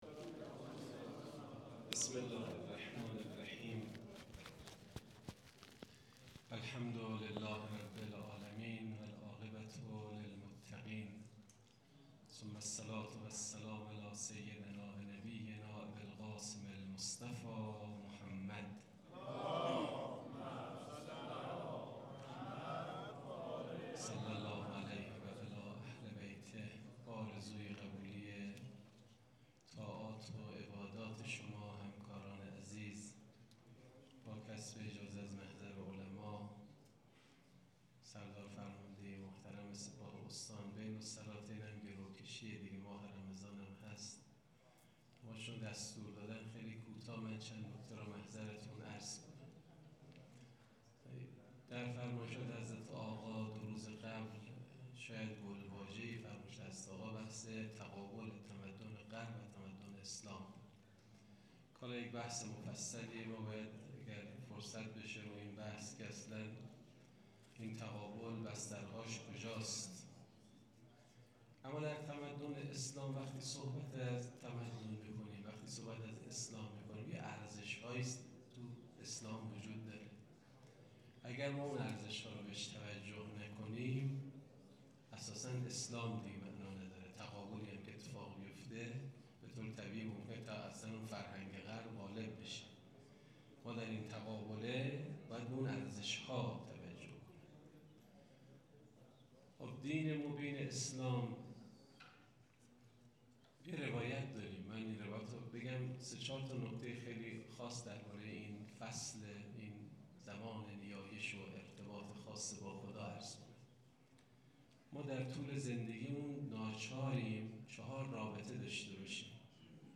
حجت الاسلام والمسلمین محمدی شاهرودی مسئول سازمان بسیج طلاب کشور در جمع رزمندگان سپاه حضرت قمربنی هاشم علیه السلام استان چهارمحال و بختیاری به تبیین اصول ارتباطی لازم و بهترین زمان برای دعا به درگاه الهی پرداخت.